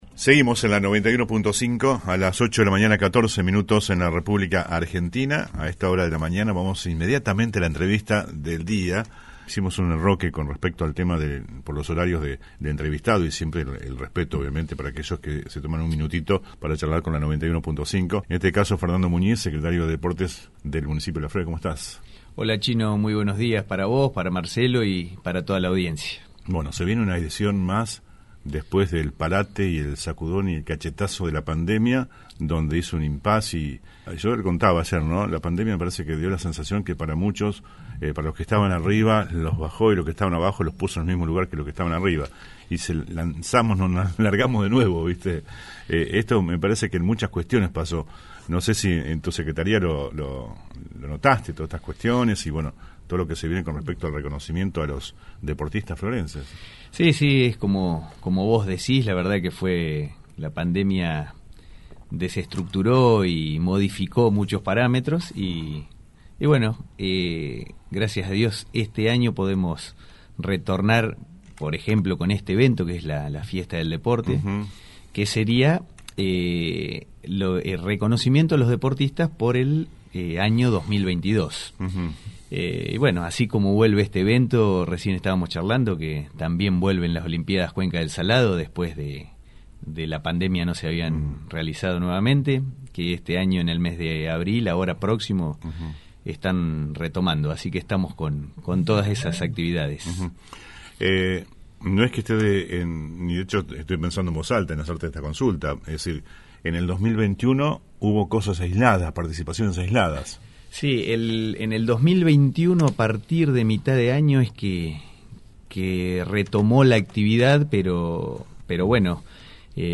Por este y distintos temas, invitamos al piso de LA RADIO, al secretario de deportes del municipio de Las Flores prof. Fernando Muñiz.
ENTREVISTA COMPLETA A FERNANDO MUÑIZ